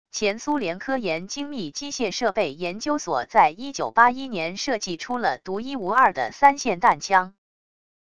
前苏联科研精密机械设备研究所在1981年设计出了独一无二的3霰弹枪wav音频